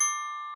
glock_D_5_2.ogg